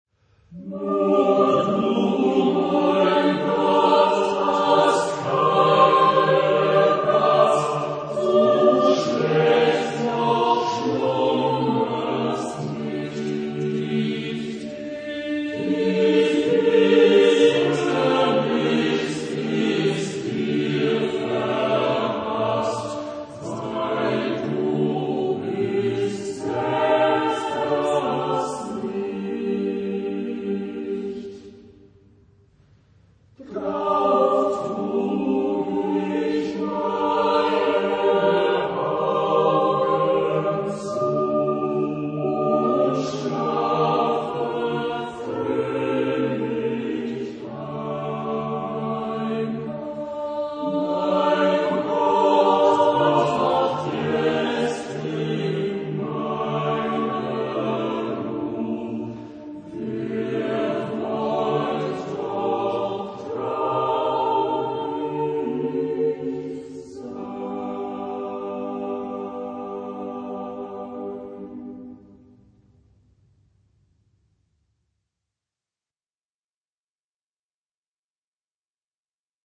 Genre-Style-Form: Partsong ; Folk music ; Sacred
Type of Choir: SATB  (4 mixed voices )
Tonality: G minor